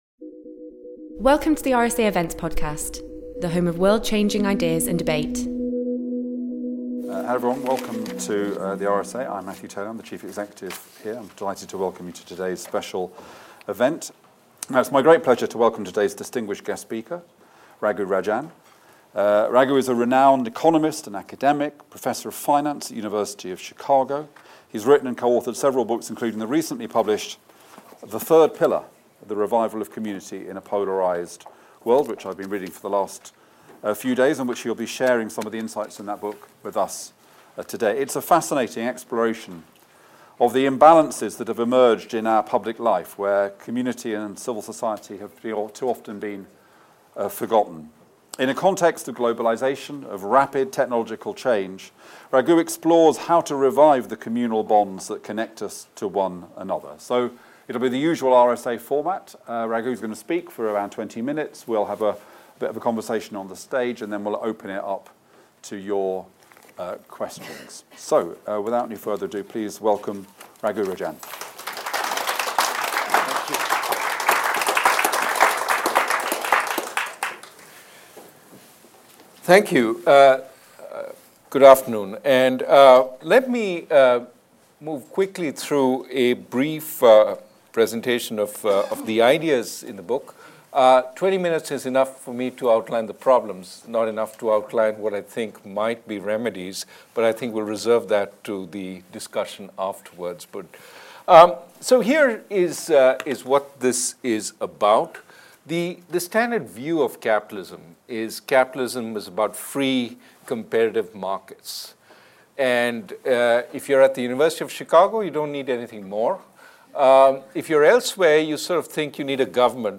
This event was recorded live at The RSA on Wednesday 13th March 2019.